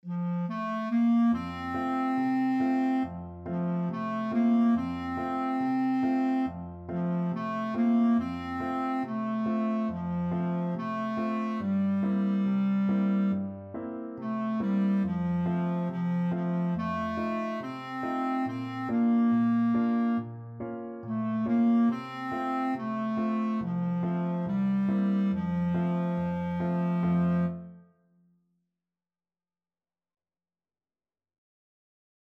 American gospel hymn.
4/4 (View more 4/4 Music)
Vivo =140
Clarinet  (View more Beginners Clarinet Music)